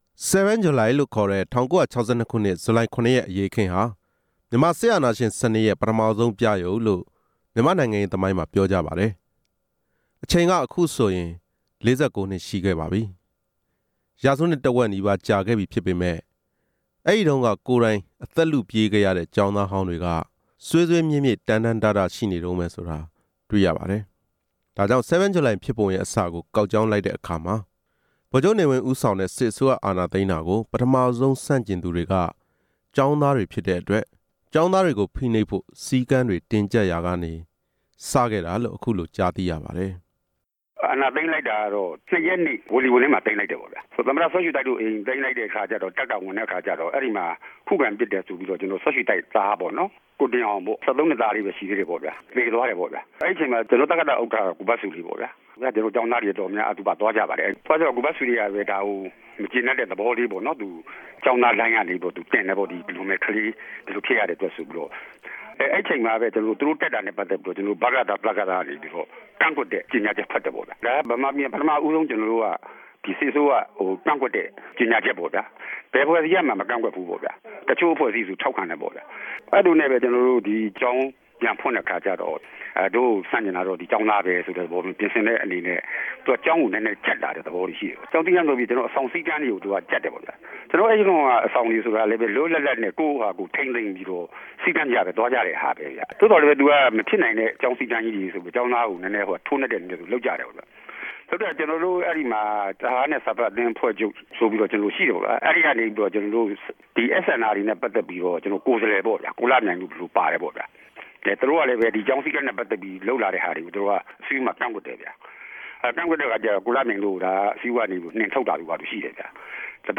၁၉၆၂ခု ဇူလိုင် ၇ ရက် ကျောင်းသား အရေးတော်ပုံ ကိုယ်တွေ့ ကြုံခဲ့သူများရဲ့ ပြောပြချက်